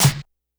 Snares
snr_55.wav